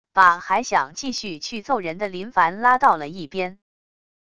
把还想继续去揍人的林凡拉到了一边wav音频生成系统WAV Audio Player